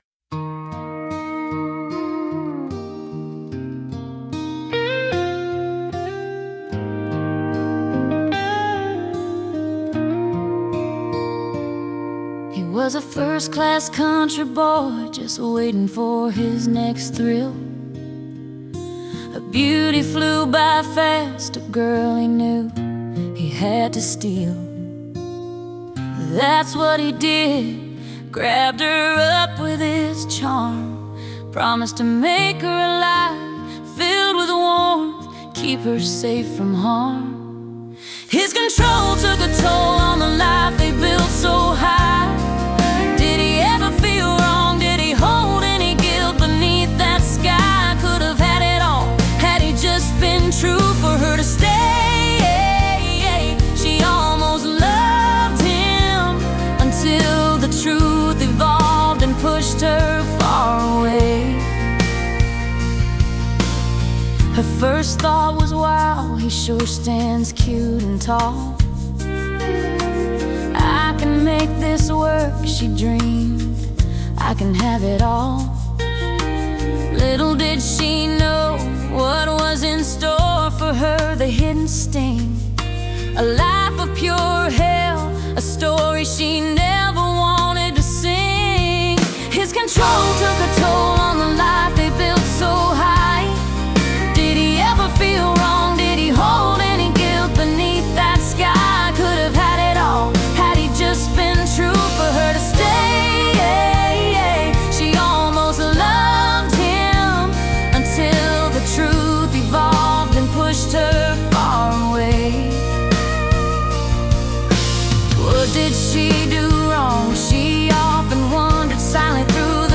Category: Country